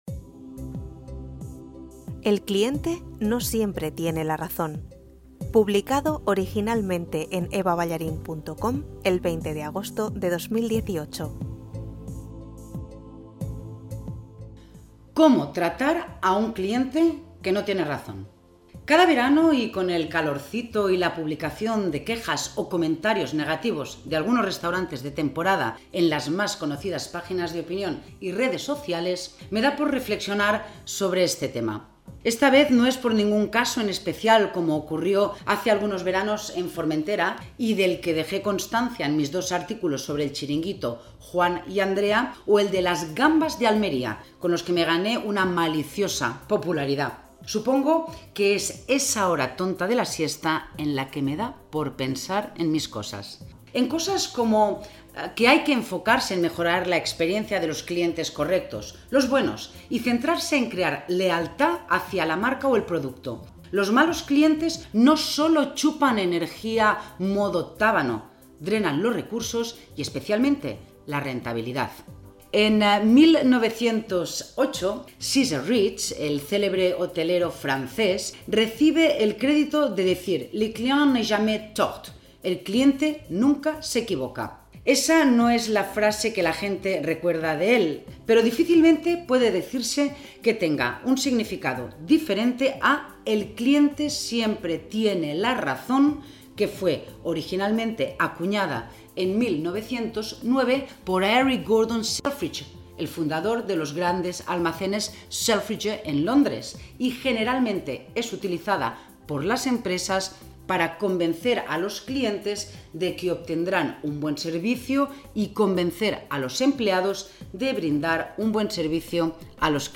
Si lo prefieres, te lo leo